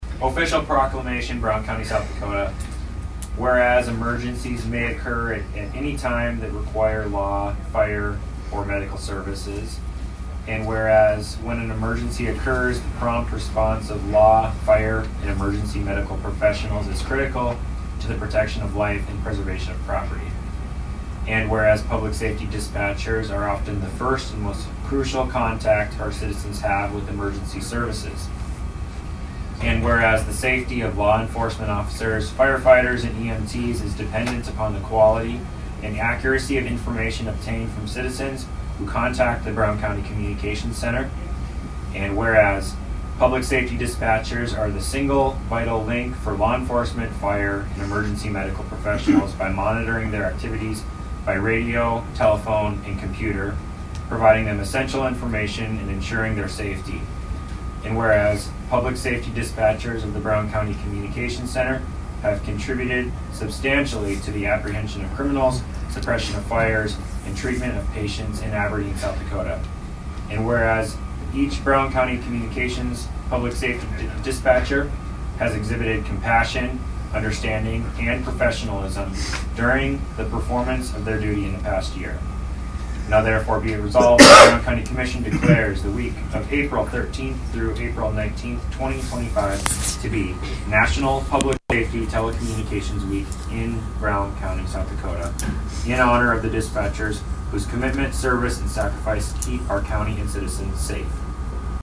At Tuesday’s Brown County Commission meeting, Commissioner Drew Dennert read the proclamation to the public.(1:41)